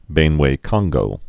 (bānwā-kŏnggō)